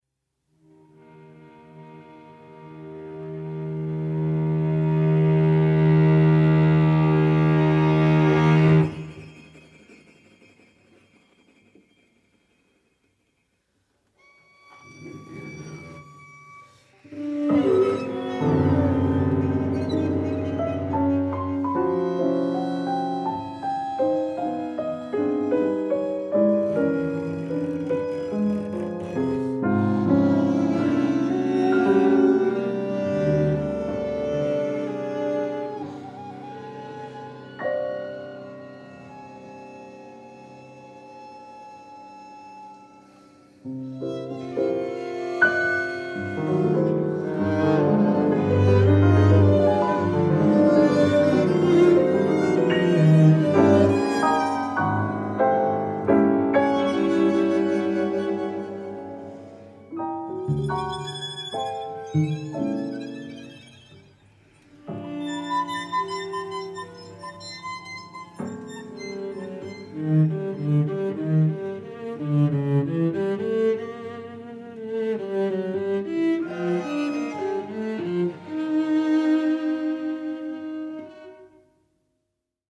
for piano quartet